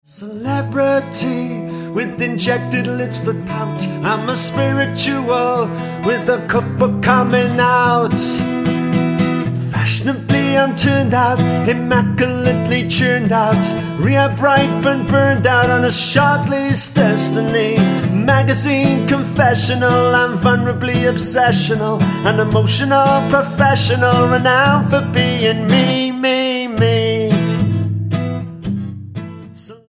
STYLE: Roots/Acoustic
Working under his own steam in a home studio